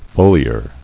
[fo·li·ar]